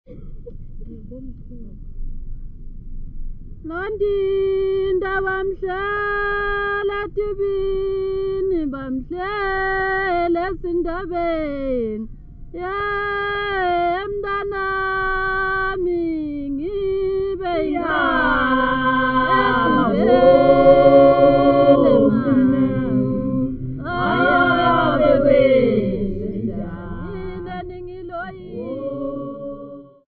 4 girls and group of men from the Queen Mother's village
Folk music--Africa
Field recordings
sound recording-musical
Unaccompanied Mhlanga ceremonial song for the reed ceremony.